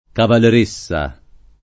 cavaleressa [ kavaler %SS a ] → cavalieressa